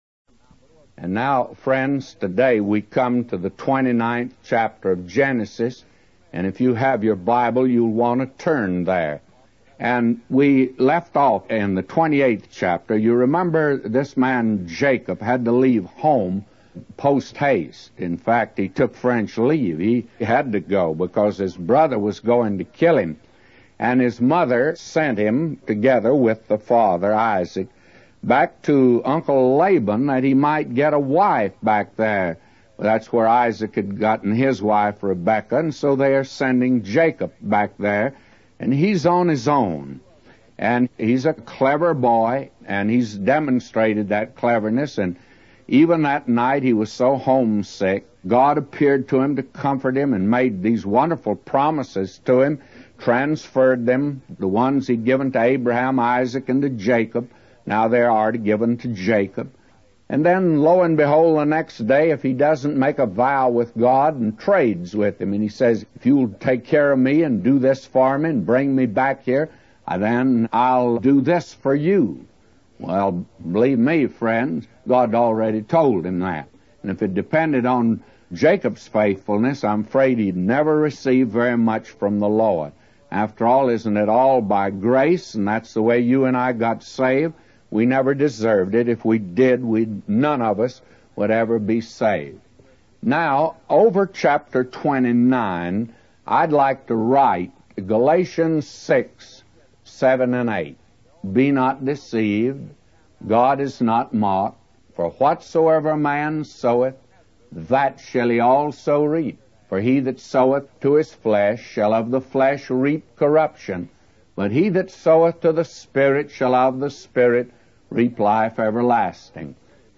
In this sermon, the preacher focuses on the 29th chapter of Genesis, where Jacob begins to face the consequences of his deceitful actions.